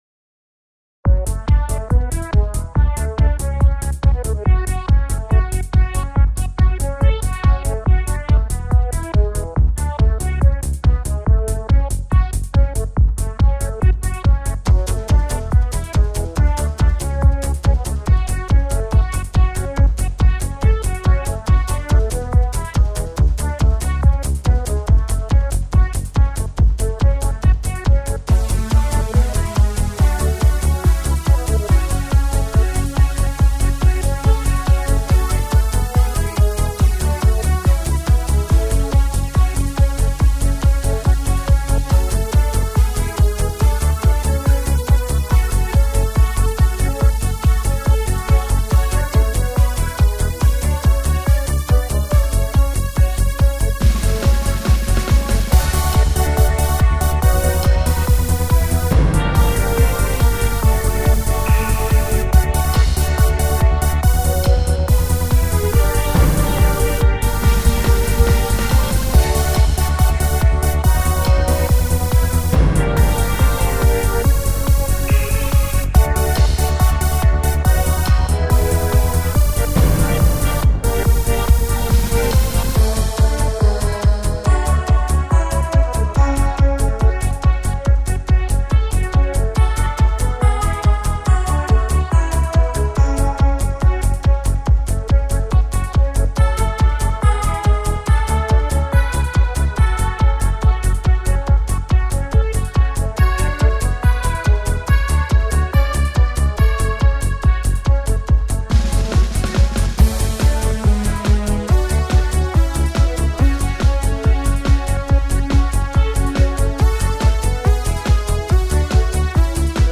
• Жанр: Танцевальная
инструментальная композиция